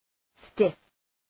Shkrimi fonetik {stıf}